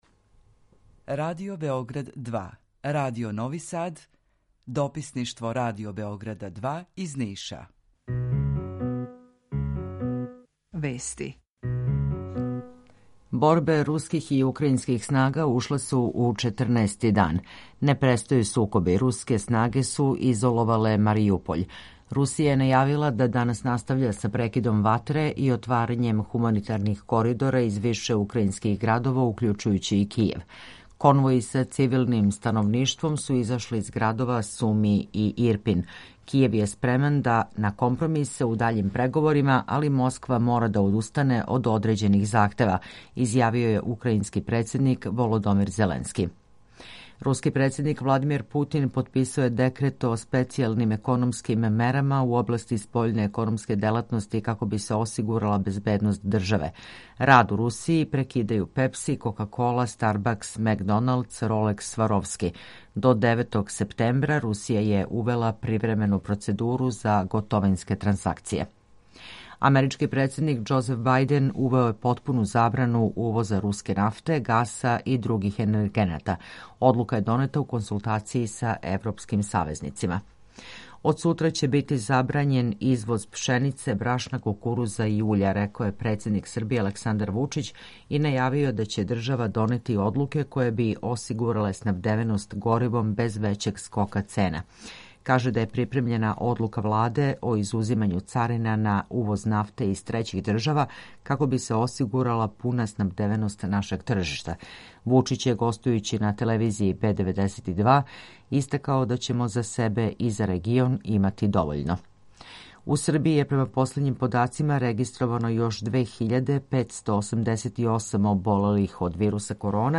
Укључење Грачанице
Јутарњи програм из три студија
У два сата, ту је и добра музика, другачија у односу на остале радио-станице.